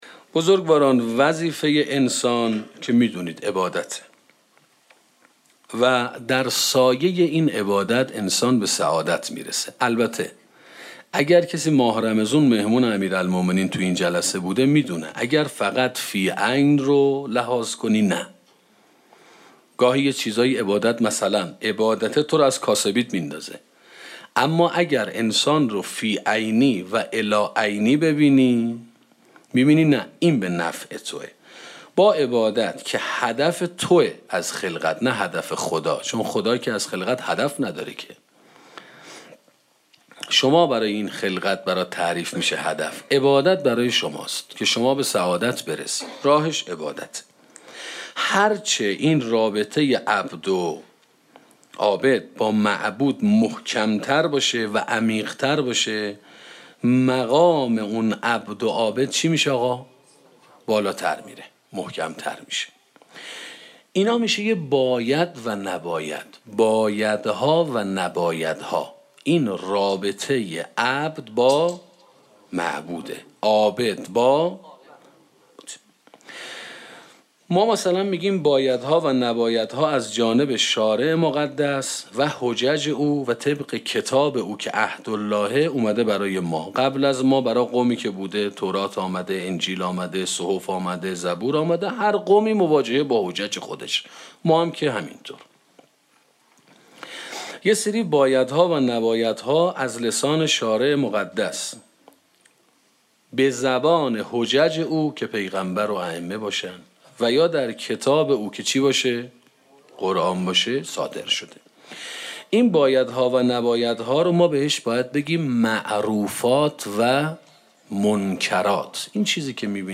سخنرانی امر به معروف و نهی از منکر